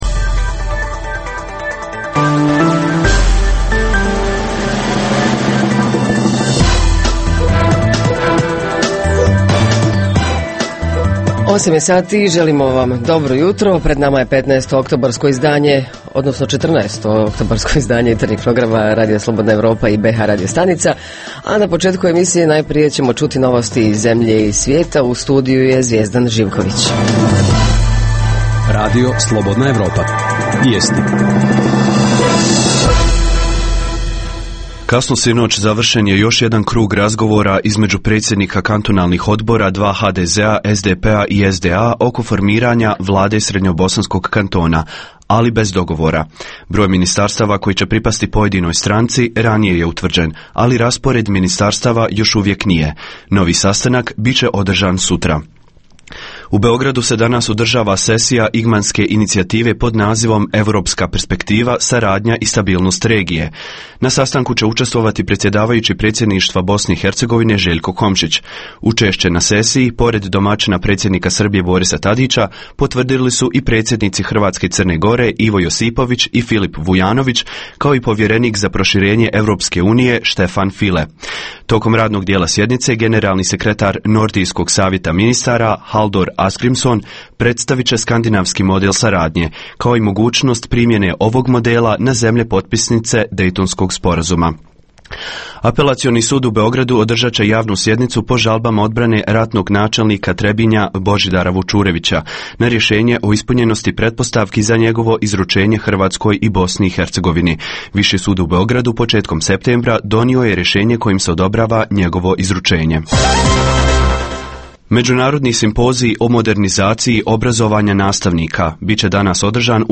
Ovog jutra pitamo šta su najčešći uzroci saobraćajnih nesreća na bh. drumovima. Reporteri iz cijele BiH javljaju o najaktuelnijim događajima u njihovim sredinama.
Redovni sadržaji jutarnjeg programa za BiH su i vijesti i muzika.